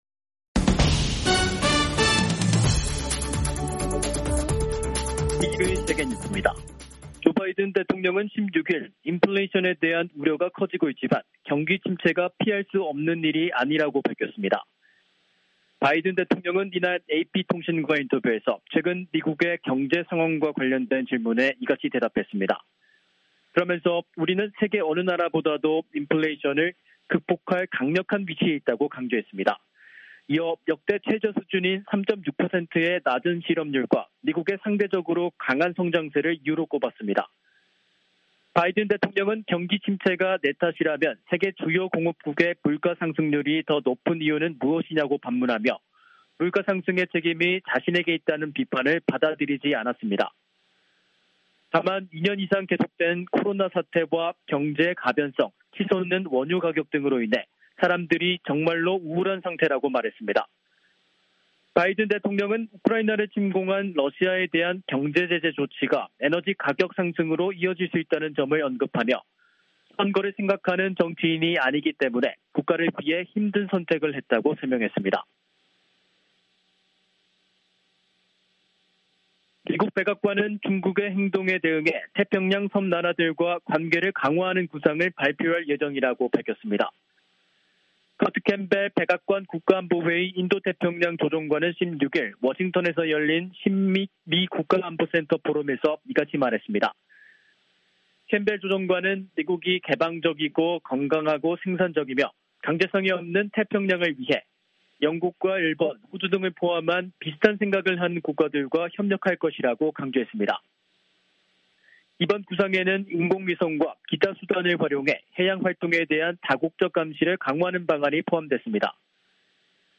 세계 뉴스와 함께 미국의 모든 것을 소개하는 '생방송 여기는 워싱턴입니다', 2022년 6월 17일 저녁 방송입니다. '지구촌 오늘'에서는 독일과 프랑스, 이탈리아 등 정상들이 우크라이나의 유럽연합(EU) 후보국 지위를 지지한 소식, '아메리카 나우'에서는 도널드 트럼프 전 대통령이 2020년 대선 결과를 뒤집기 위해 마이크 펜스 전 대통령을 전방위적으로 압박했다는 청문회 증언 전해드립니다.